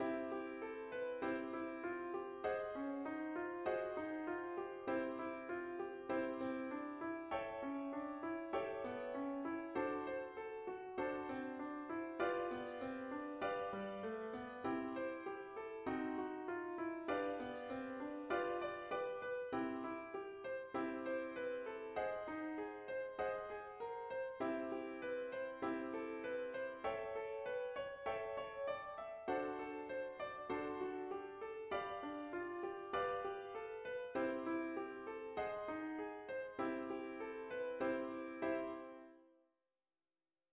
Here I show an example to illustrate the use of the motive and its repetition.
Sequence- 3 Sequence Improvisation
Obviously this is a very rudimentary example but it does show how a repeated motive can give your improvised solo some unity just as Beethoven used his three short notes and one long note to give continuity to each of his movements in his 5th Symphony.